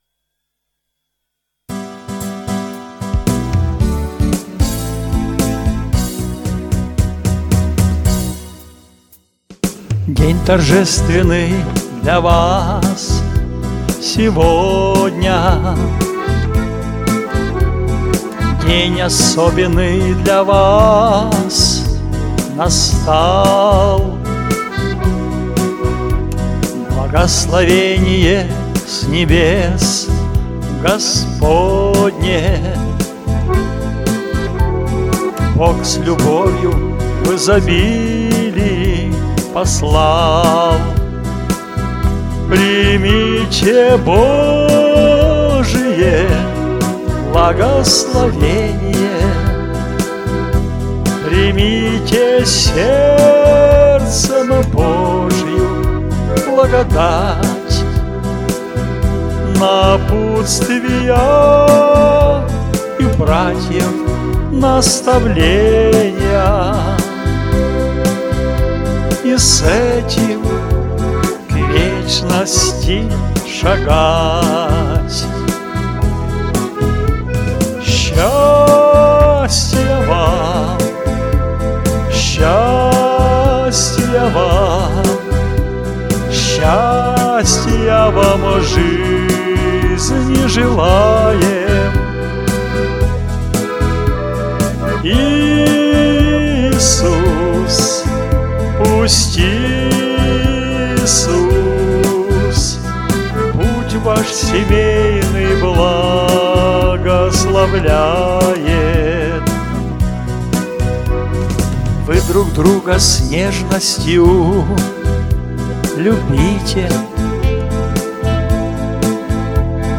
Теги: Христианские песни